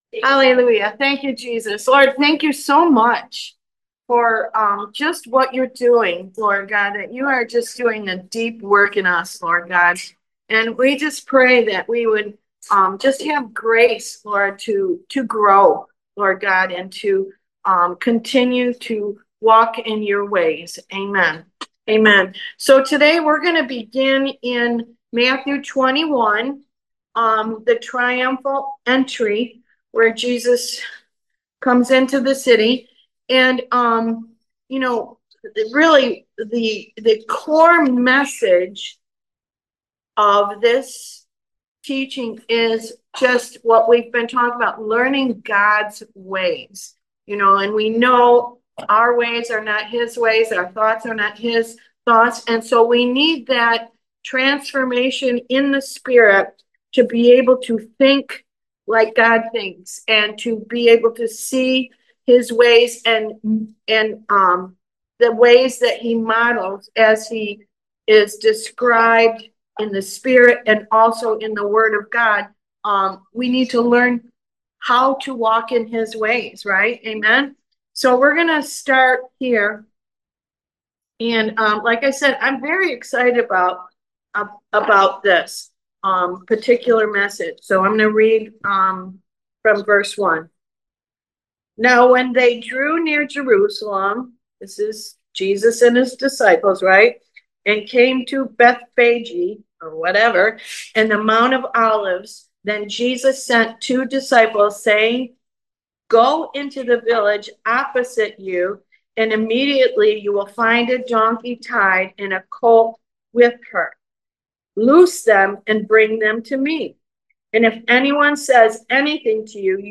Service Type: Why Jesus Class